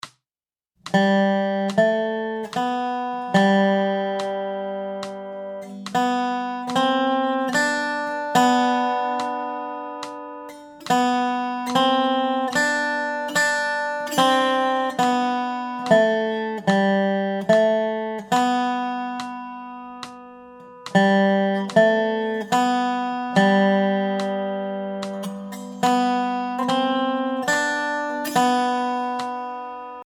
Voicing: Dobro Method